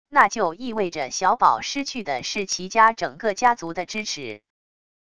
那就意味着小宝失去的是齐家整个家族的支持wav音频生成系统WAV Audio Player